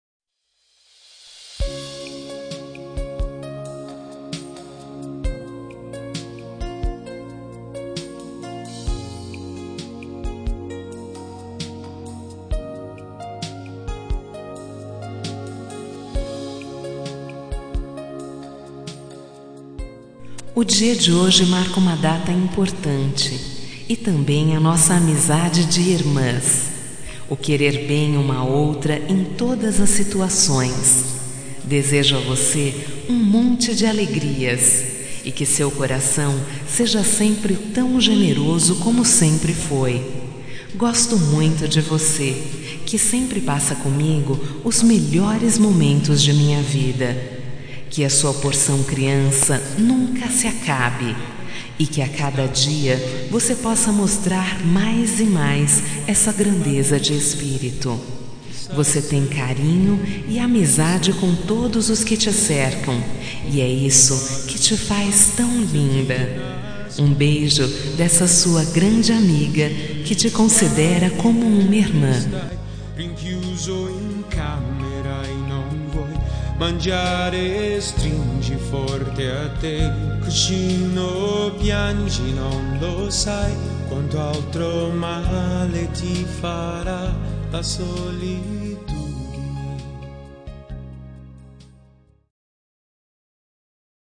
Telemensagem Aniversário de Amiga – Voz Feminina – Cód: 1524 – Como Irmã